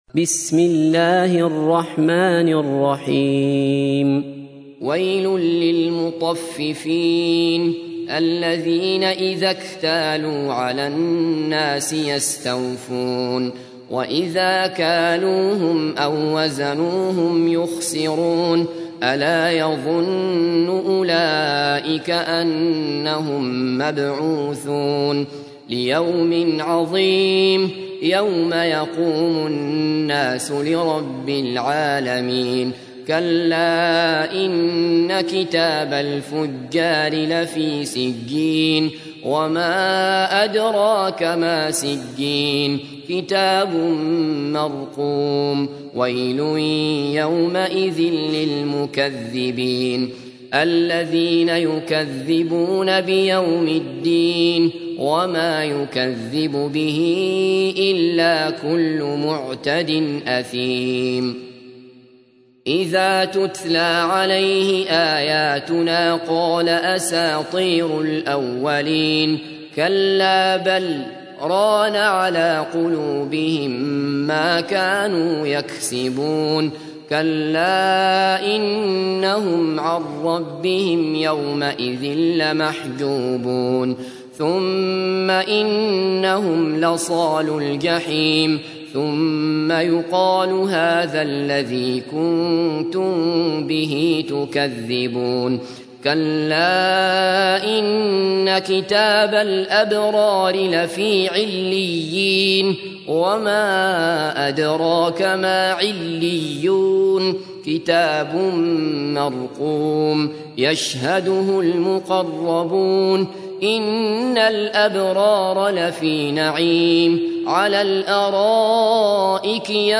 تحميل : 83. سورة المطففين / القارئ عبد الله بصفر / القرآن الكريم / موقع يا حسين